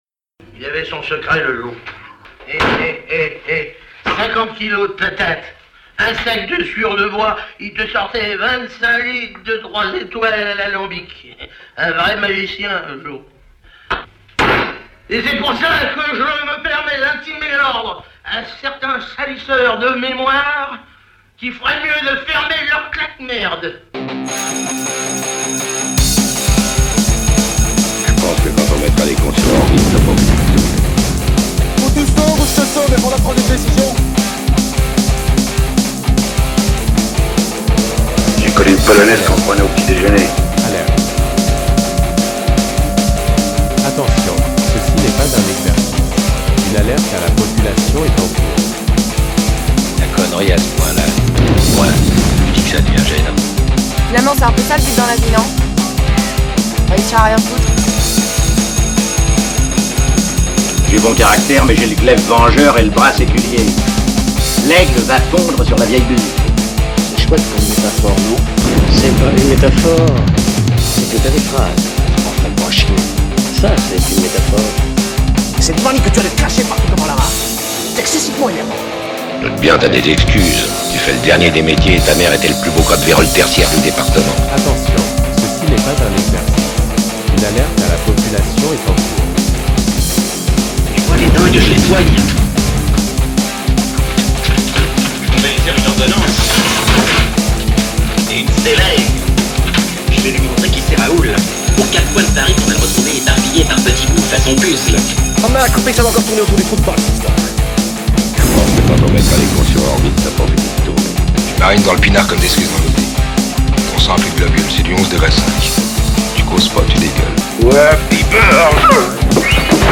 Punk US